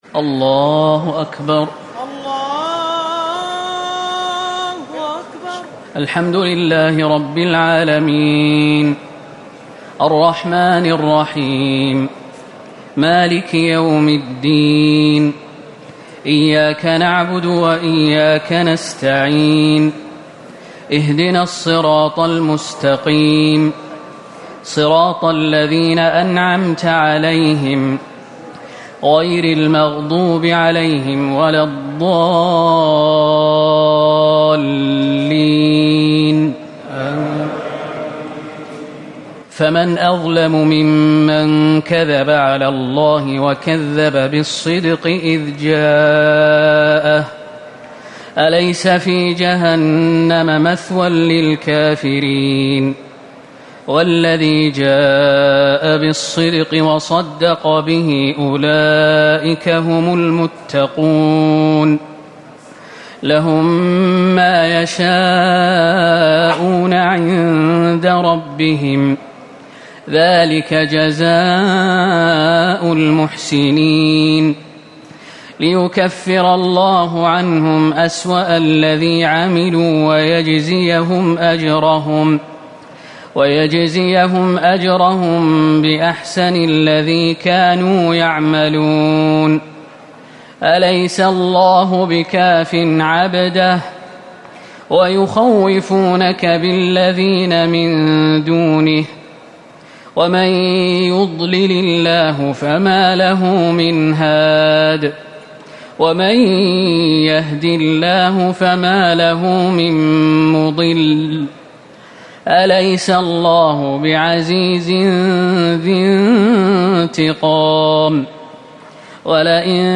تراويح ٢٣ رمضان ١٤٤٠ من سورة الزمر ٣٢ - غافر ٣٧ > تراويح الحرم النبوي عام 1440 🕌 > التراويح - تلاوات الحرمين